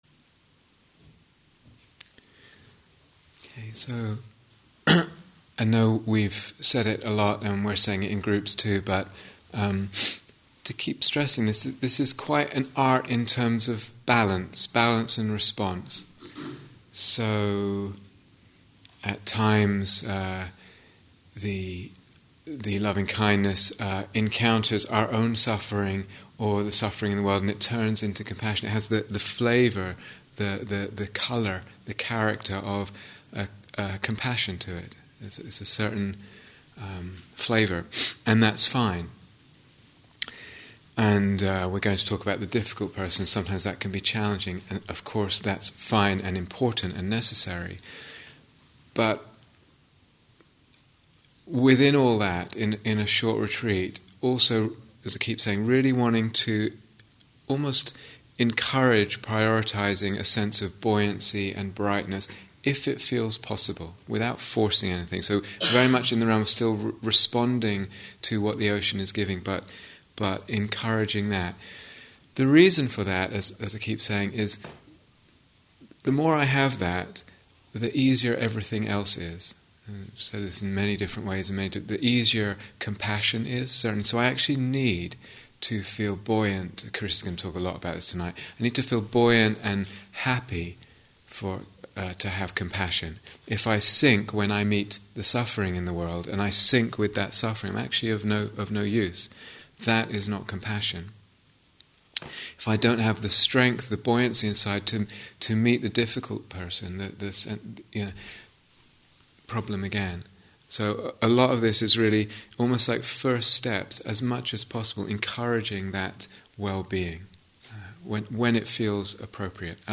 Mettā Instructions and Guided Meditation 4